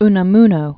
U·na·mu·no
(nə-mnō, -nä-), Miguel de 1864-1936.